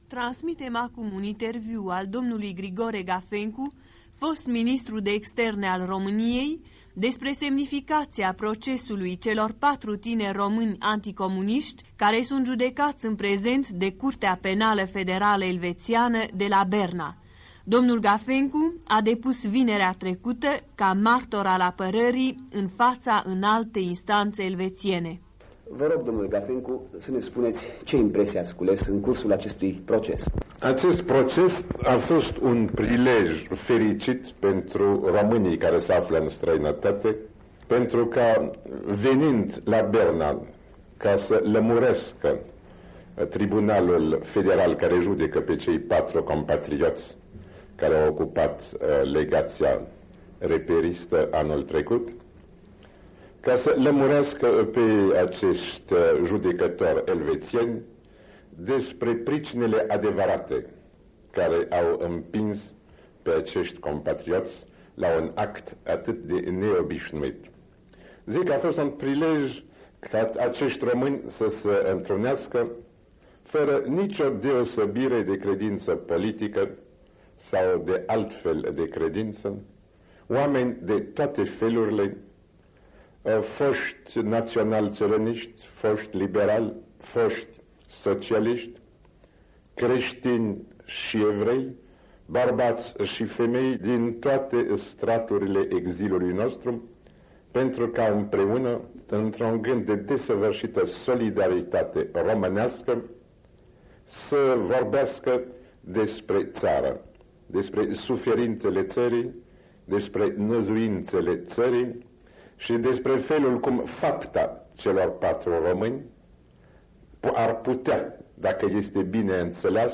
Interviul acordat de Grigore Gafencu Europei Libere și lui Noël Bernard în 1956